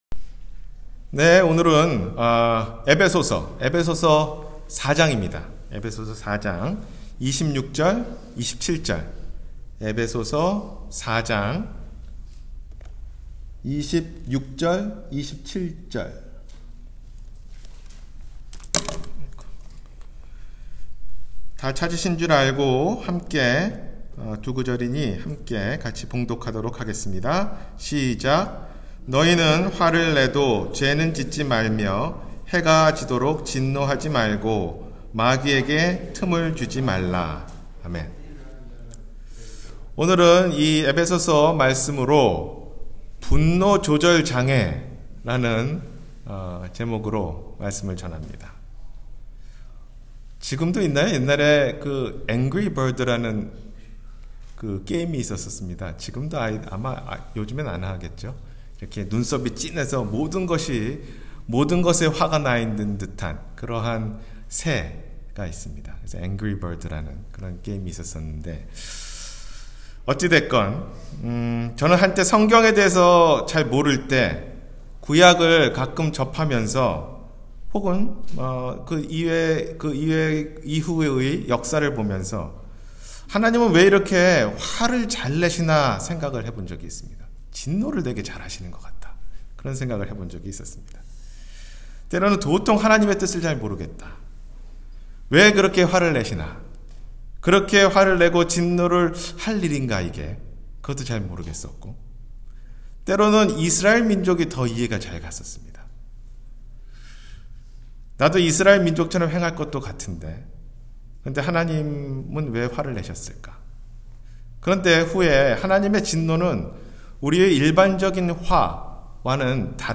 분노조절장애-주일설교